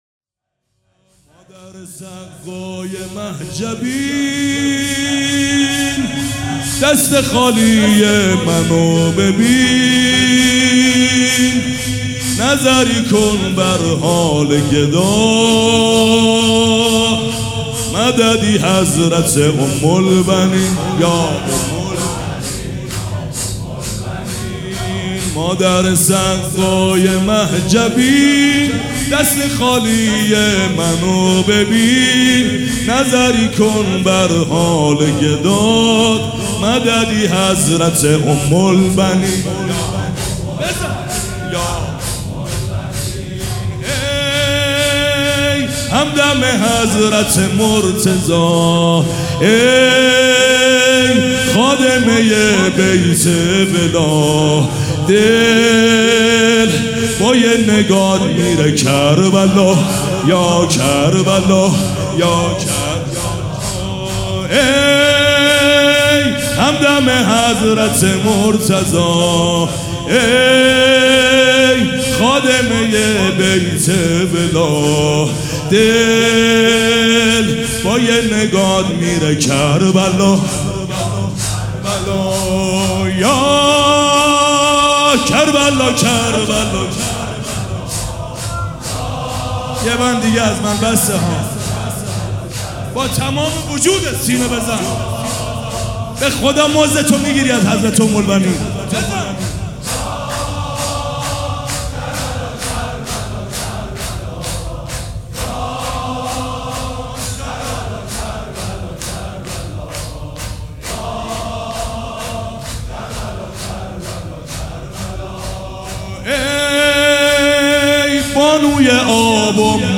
حسینیه ریحانة‌الحسین (س)
سبک اثــر شور
وفات حضرت ام البنین (س)